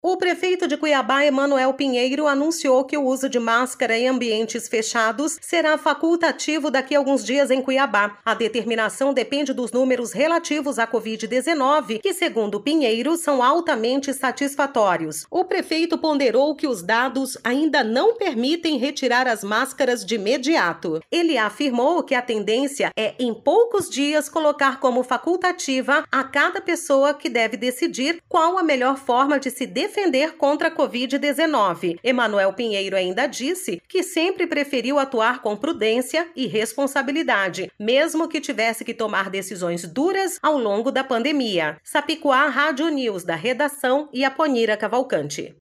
Sapicuá Comunicação – Assessoria e Produções em Áudio Radioagência Sápicuá de Notícias